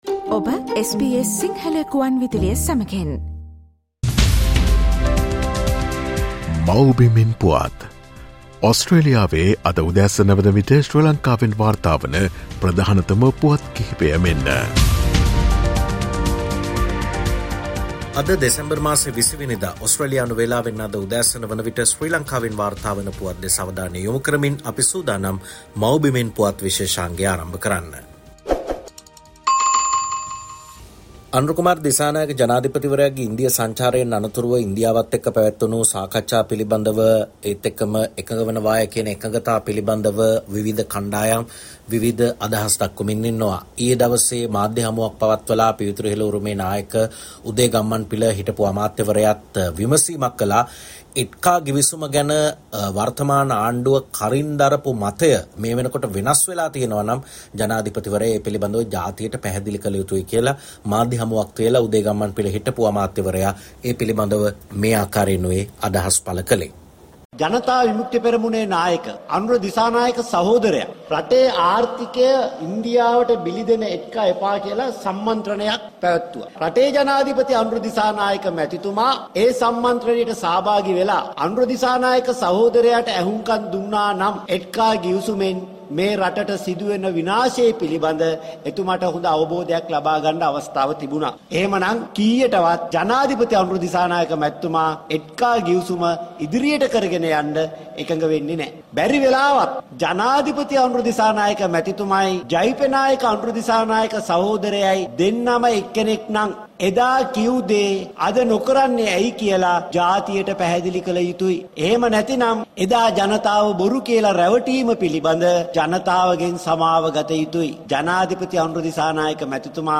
SBS Sinhala reporter and senior journalist